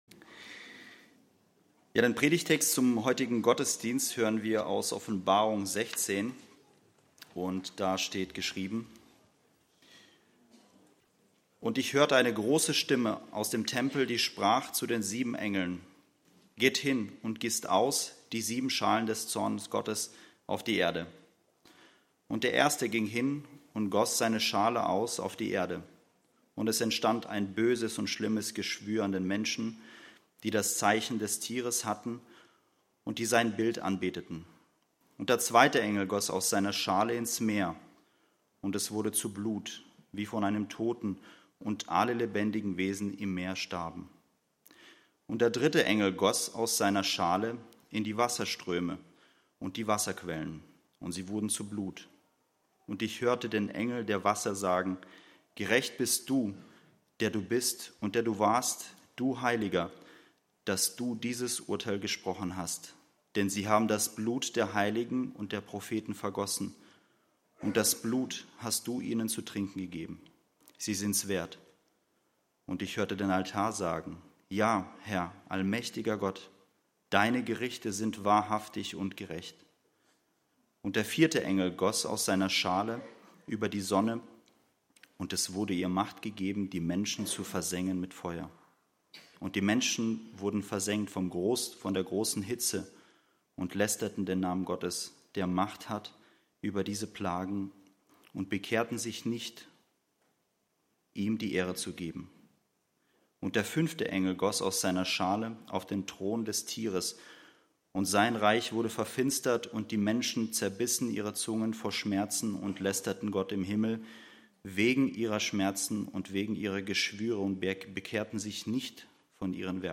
Die sieben Schalen des Zornes Gottes (Offb. 16, 1-21) - Gottesdienst